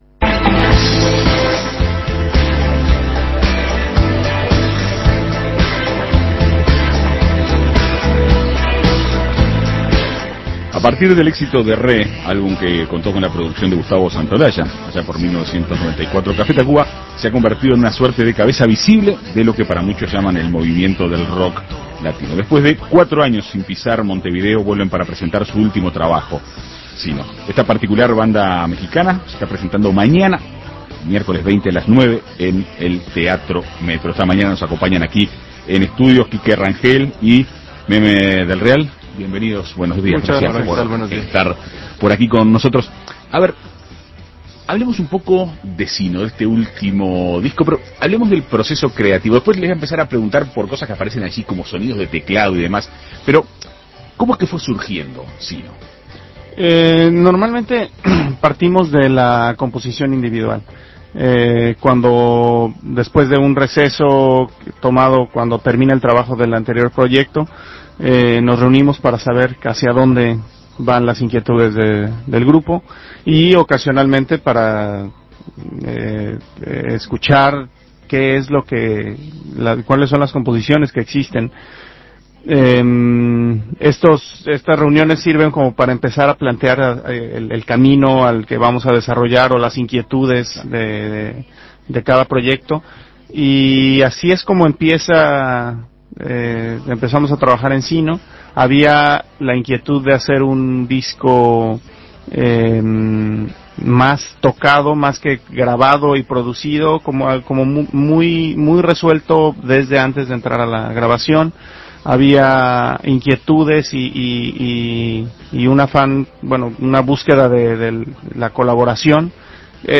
La banda mexicana Café Tacuba presenta este miércoles 9, en el Cine Metro, su último trabajo ("Sino"). En Perspectiva Segunda Mañana dialogó con dos de sus integrantes, Quique Rangel y Emmanuel "Meme" del Real, para conocer más acerca de su nuevo disco.